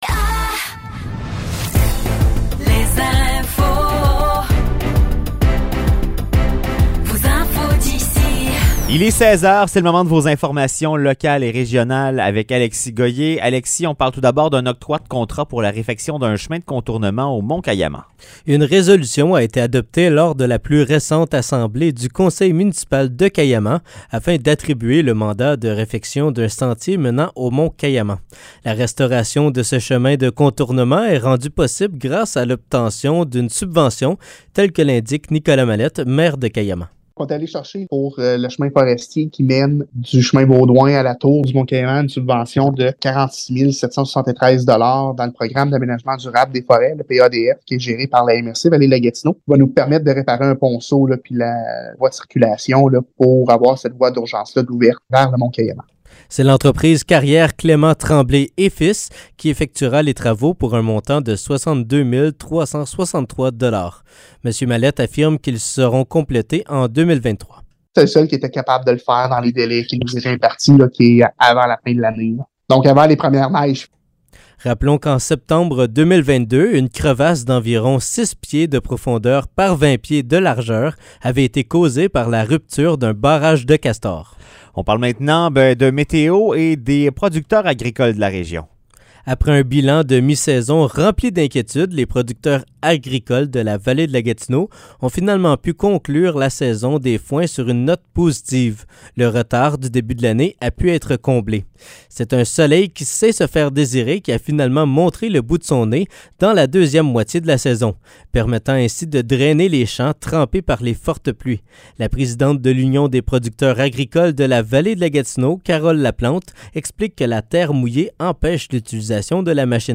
Nouvelles locales - 21 septembre 2023 - 16 h